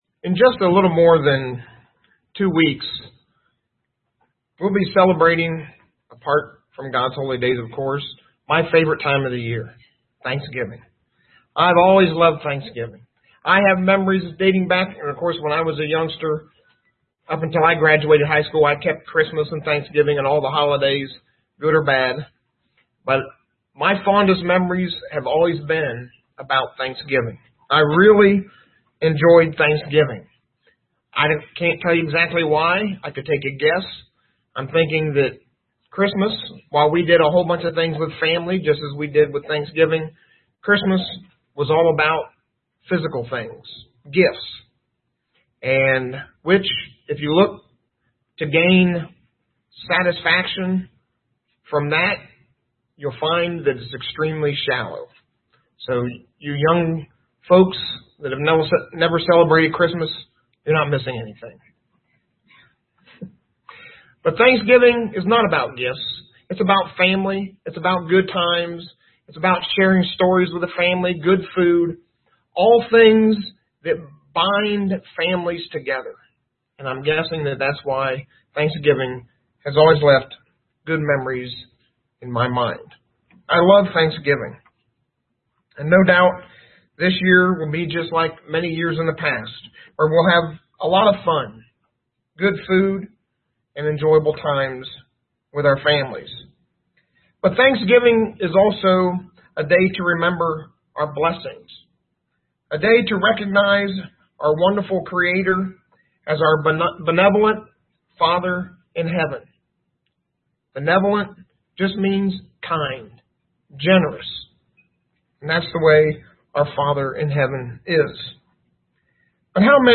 UCG Sermon Notes Notes: Abraham Lincoln saw that the United States was a blessed nation.